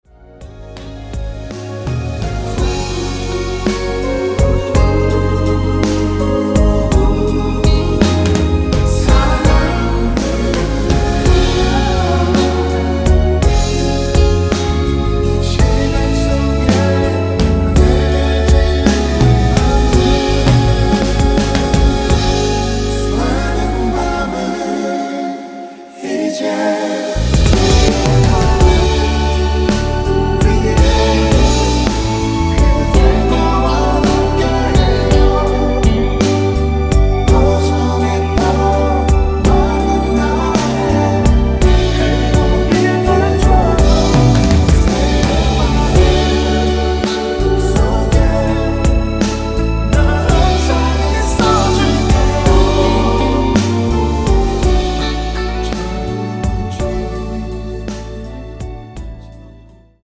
원키에서(-1)내린 멜로디와 코러스 포함된 MR입니다.(미리듣기 참조)
Db
앞부분30초, 뒷부분30초씩 편집해서 올려 드리고 있습니다.
중간에 음이 끈어지고 다시 나오는 이유는